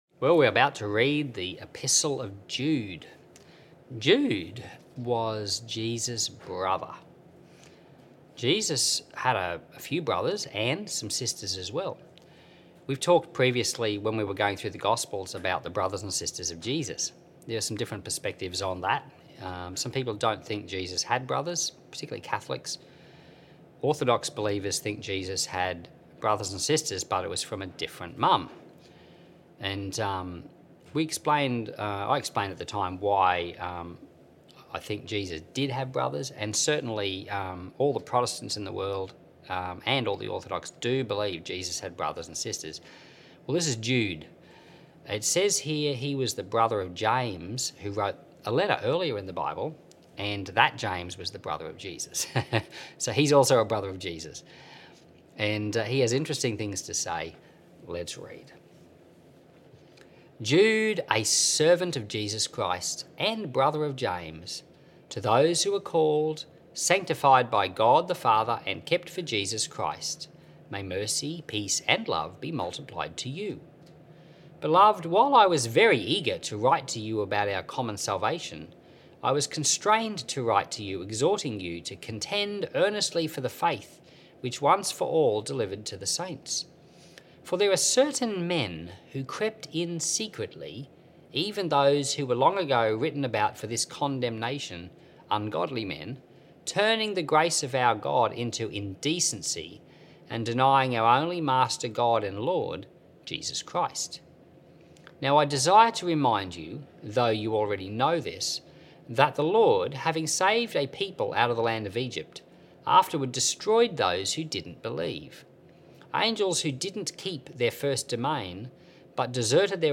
as he reads Jude 1 and then discusses the chapter and these issues.